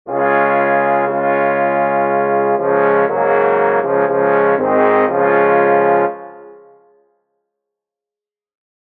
The melody is built out of the intervals of the common chord—the triad—the starting-point of harmony, its first and most pervasive law. This chord, too, supplies the harmonic structure. Its instrumentation (for four tubas with peculiarly orotund voices, specially constructed for Wagner) is unvarying, calm, stately, majestic, dignified, reposeful.